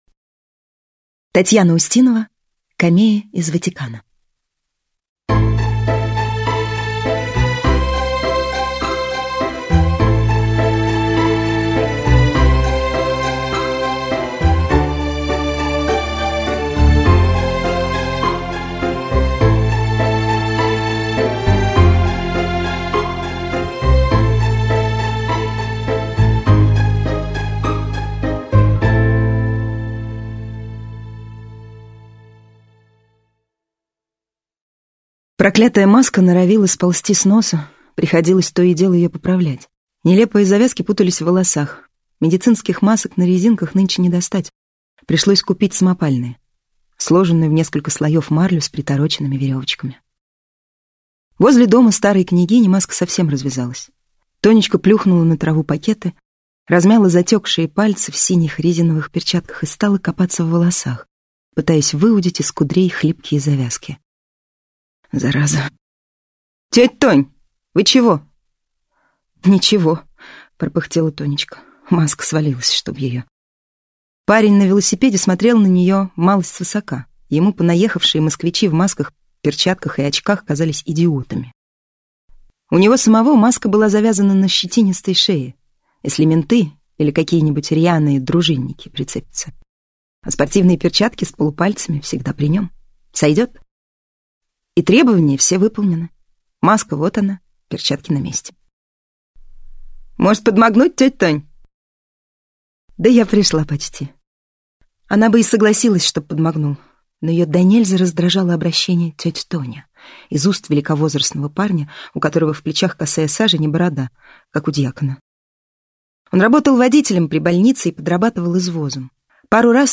Аудиокнига Камея из Ватикана - купить, скачать и слушать онлайн | КнигоПоиск